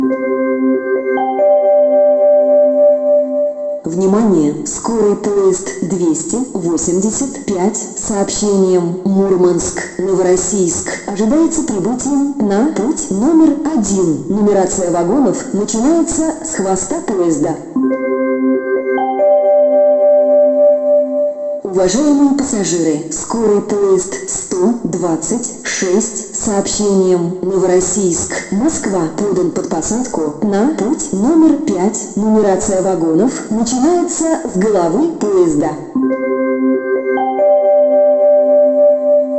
Звуки жд вокзала
Погрузитесь в атмосферу железнодорожного вокзала с нашей коллекцией звуков: шум поездов, переговоры пассажиров, стук колес и электронные голоса диспетчеров.
Звуки вокзала Новороссийска